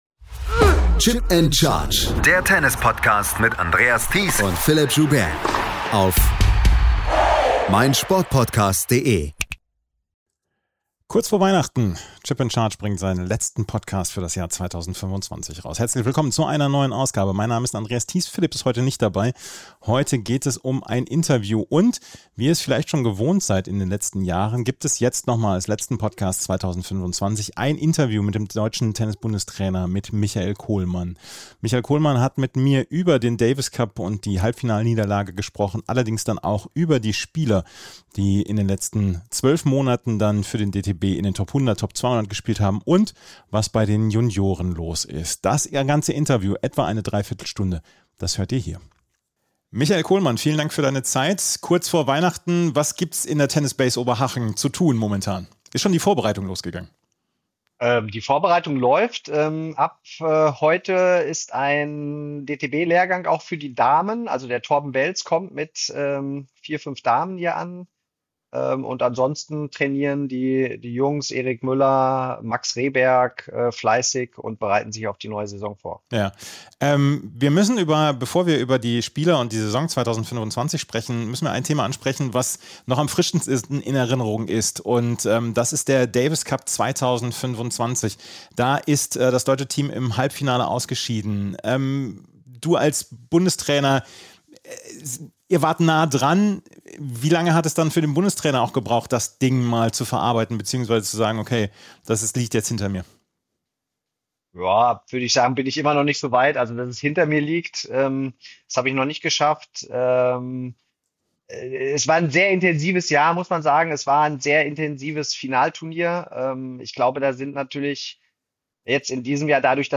Willkommen zur 98. und letzten Episode von Chip & Charge 2025 - heute mit einem ausführlichen Interview mit Michael Kohlmann.